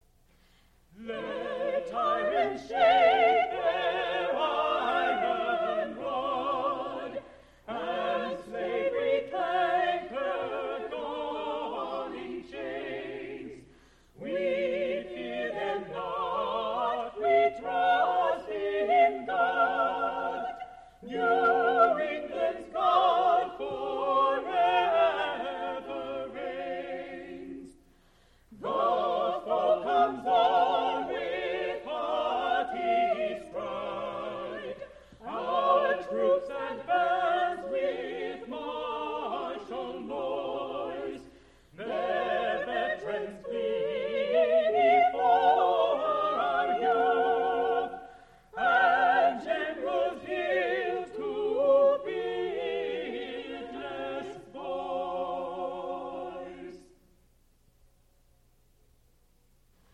Spoken intro for How Cold It Is, Chester, and Broad is the Road that Leads to Death